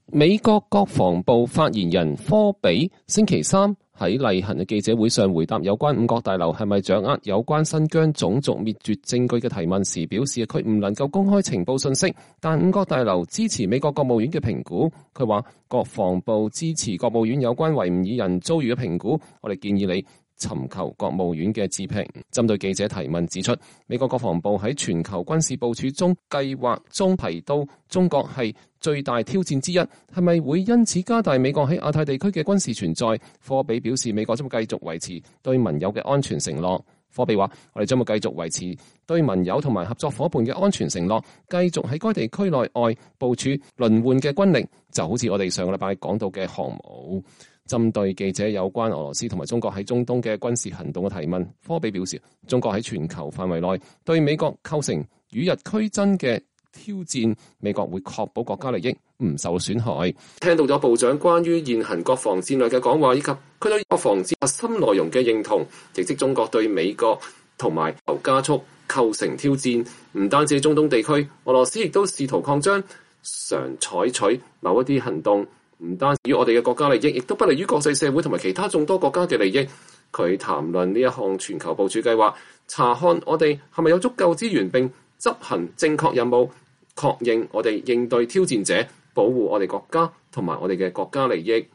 美國國防部發言人科比。